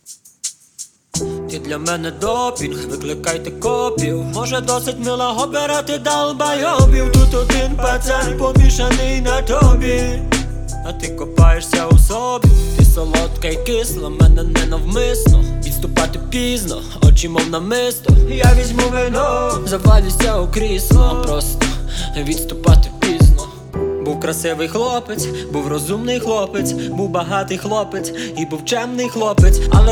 Жанр: Рэп и хип-хоп / Иностранный рэп и хип-хоп / Украинские